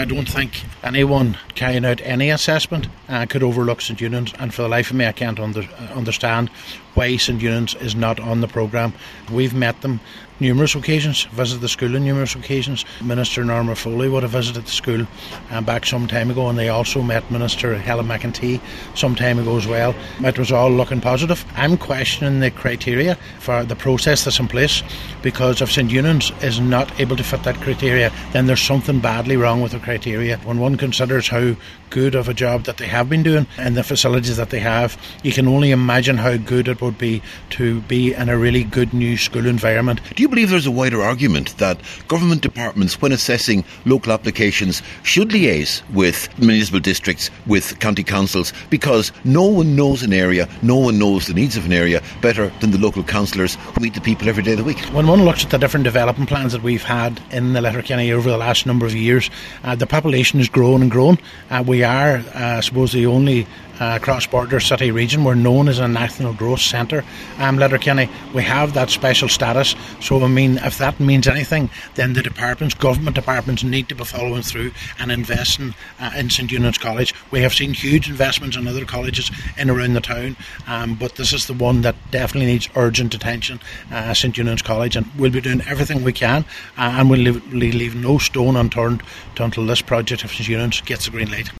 Mayor Cllr Ciaran Brogan says their campaign continues………….